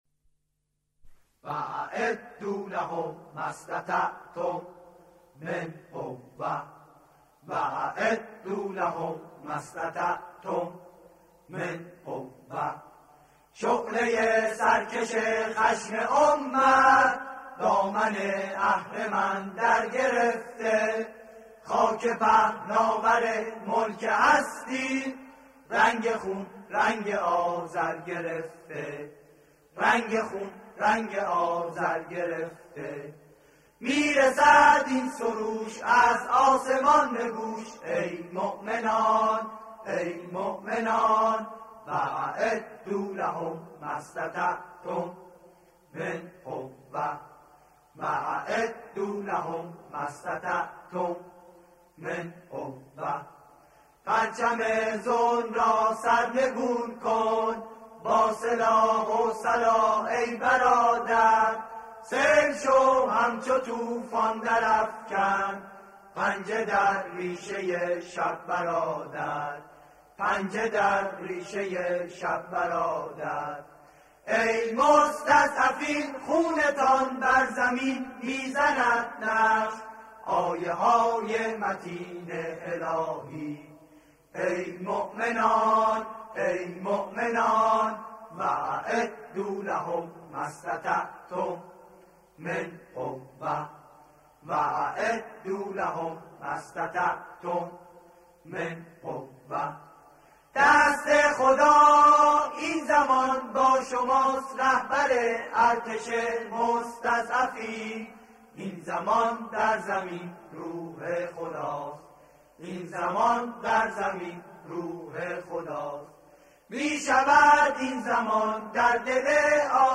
آکاپلا
به صورت آکاپلا اجرا می‌شود